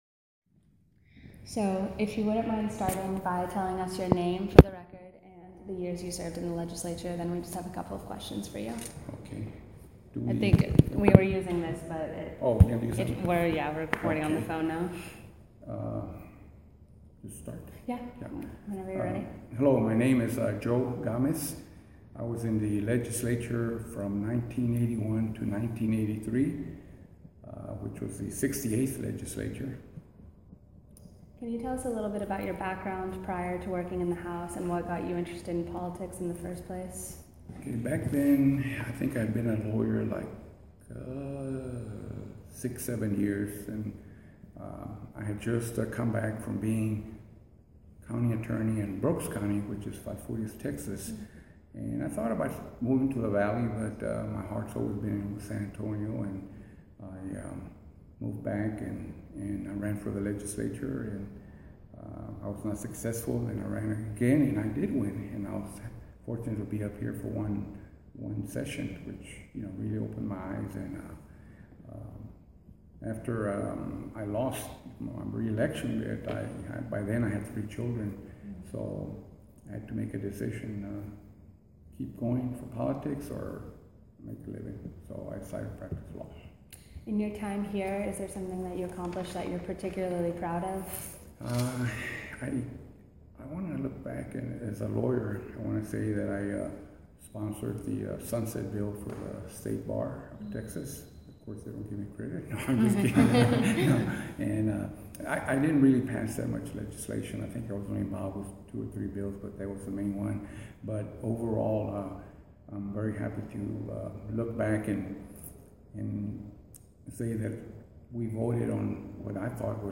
Biographical Sketches Oral history interview with Joe Gamez, 2015. Texas House of Representatives .